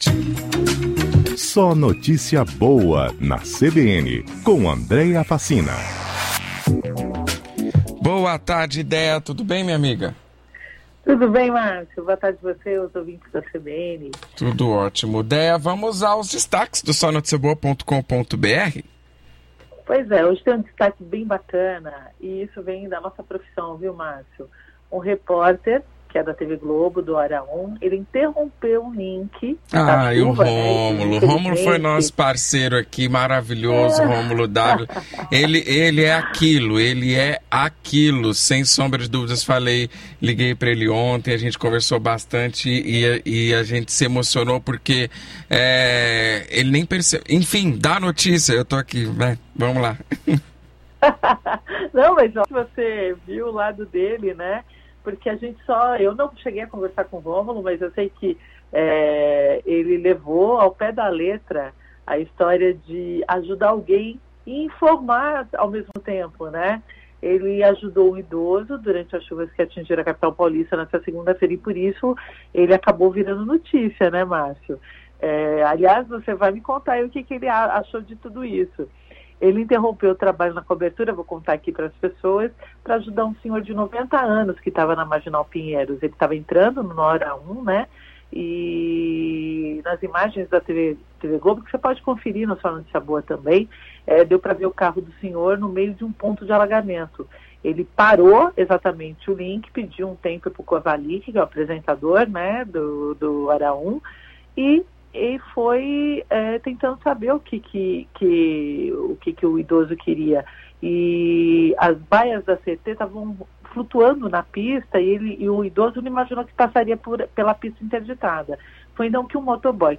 O quadro SNB na CBN vai ao ar de segunda a sexta às 16:55 na rádio CBN Grandes Lagos.